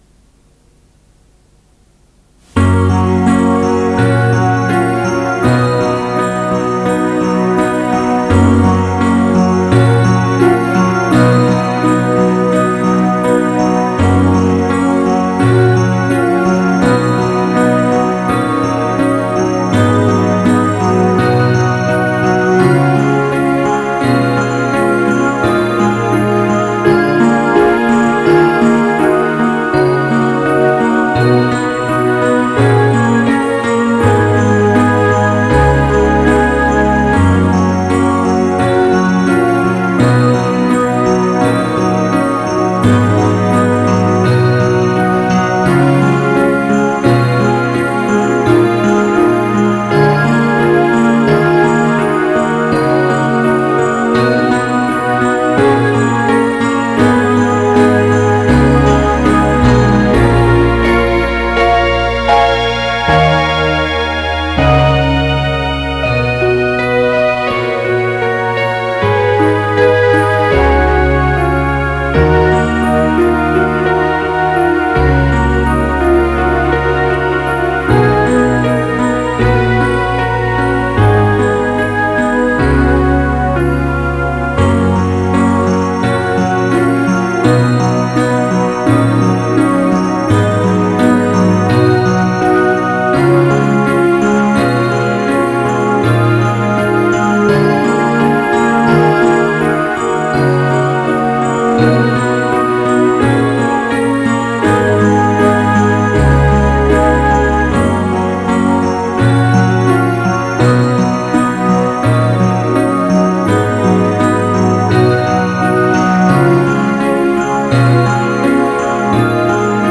Instrumental (Audio)